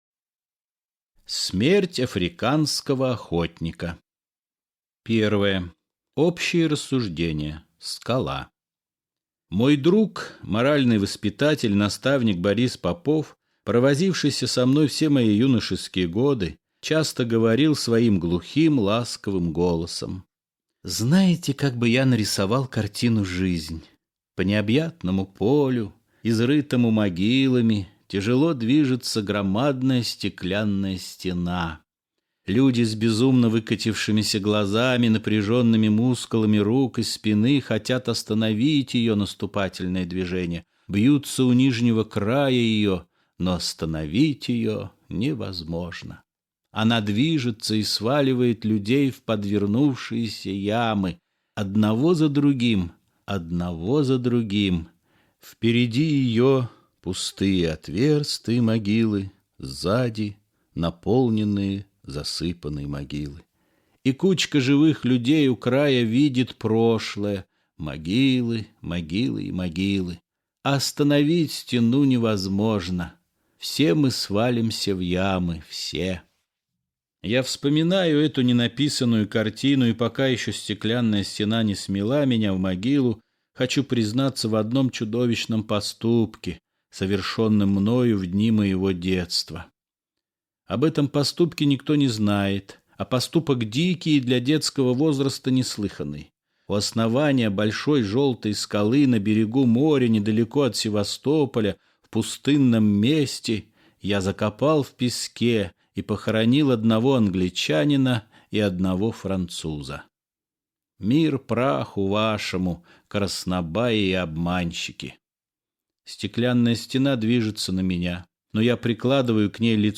Смерть африканского охотника - аудио рассказ Аверченко - слушать онлайн